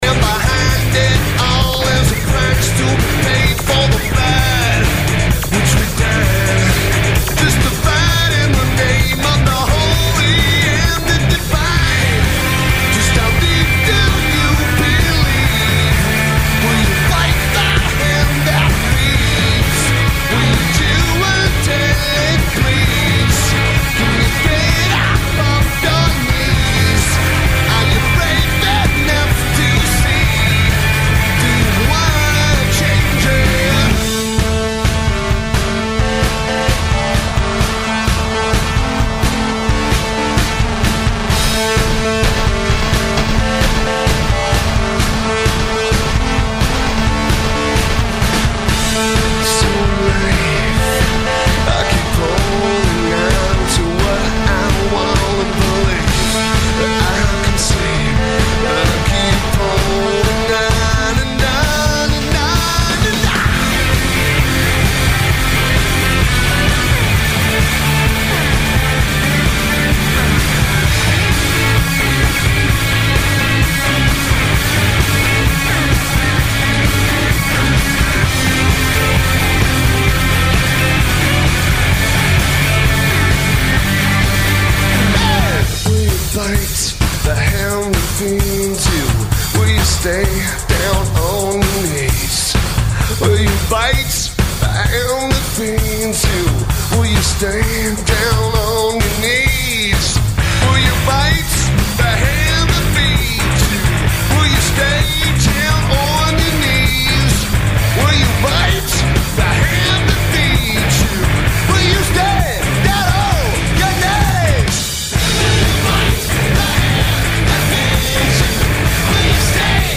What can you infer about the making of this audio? Lineage: Audio - FM (FM Braodcast)